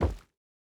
added stepping sounds
Rubber_02.wav